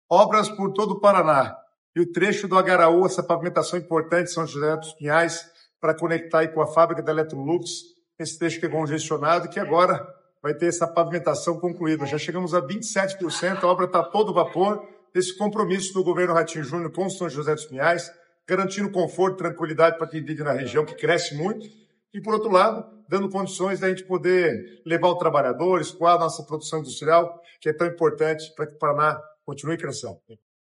Sonora do secretário das Cidades, Guto Silva, sobre a pavimentação da estrada do Agaraú